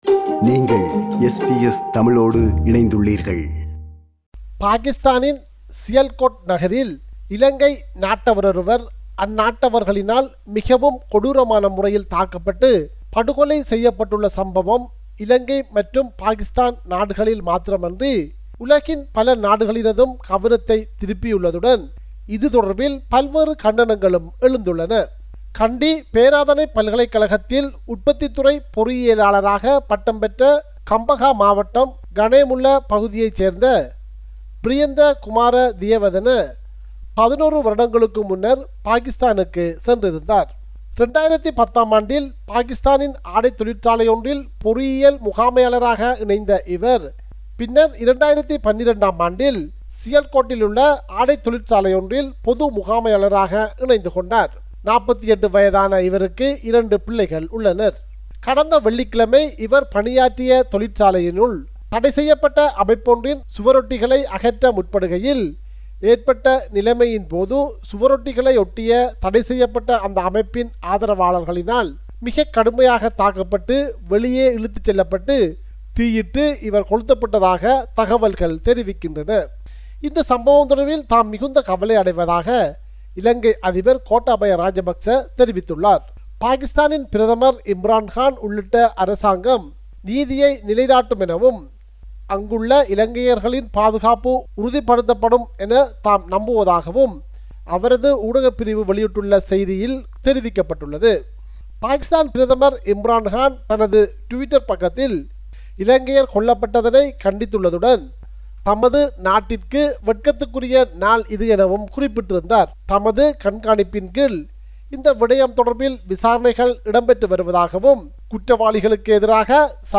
compiled a report focusing on major events/news in North & East / Sri Lanka.